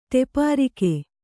♪ tepārike